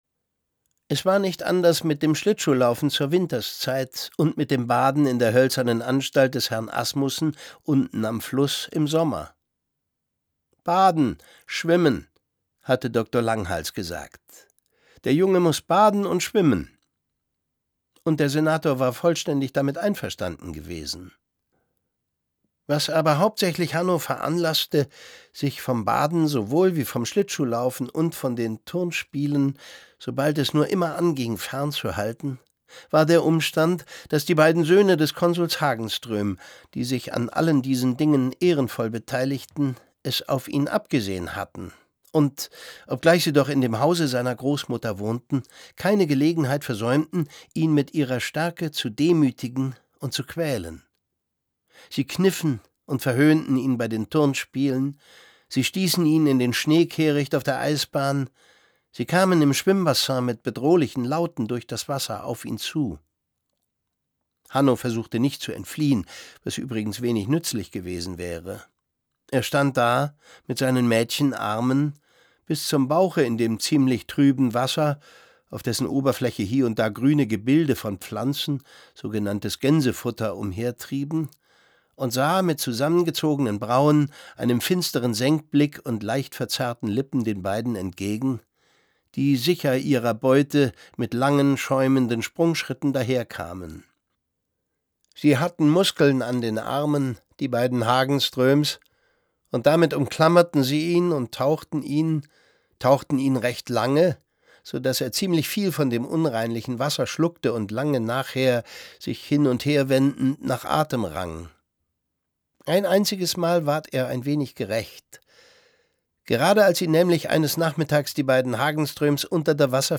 Hanno spielt dazu Musik und macht Theater, anstatt zu den schulischen „Turnspielen“ zu gehen, wie es sein Vater wünscht. Es liest Thomas Sarbacher.